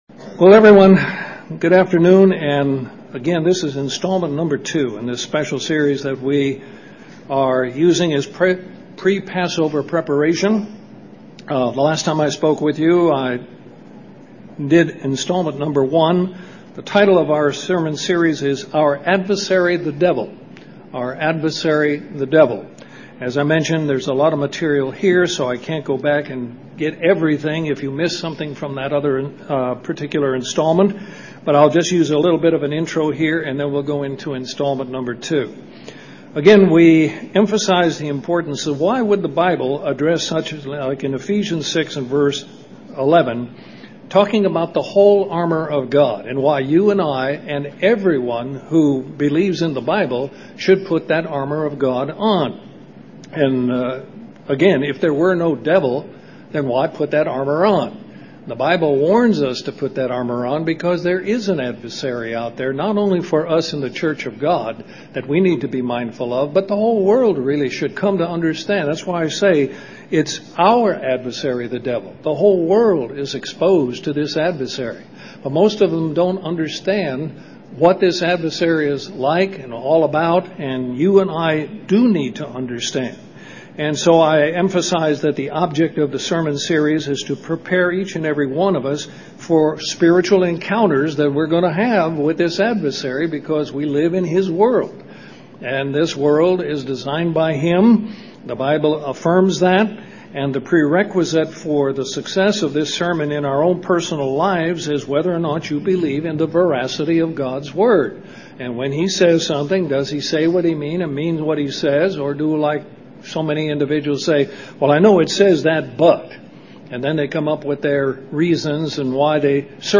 A continuation of the sermon series on learning to recognize our enemy and how to deal with him.
Given in Columbus, GA Central Georgia